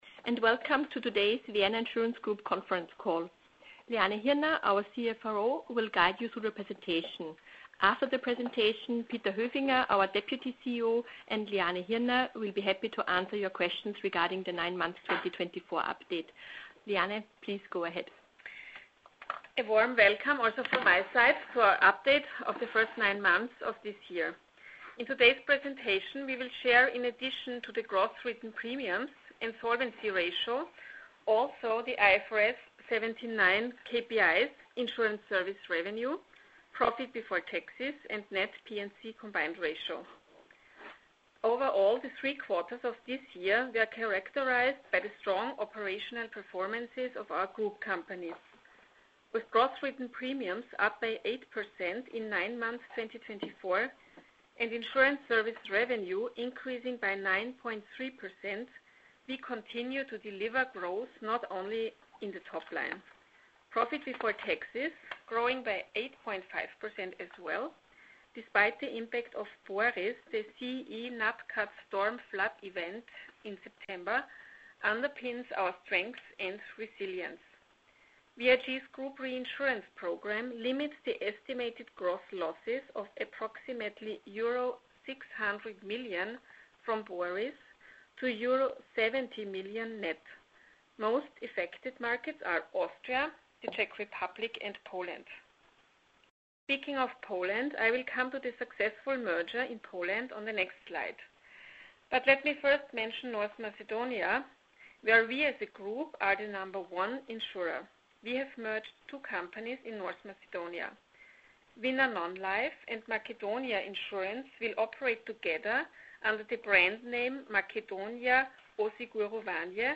2024 Vorläufiges Ergebnis VIG Telefonkonferenz (english only) mp3 Datei herunterladen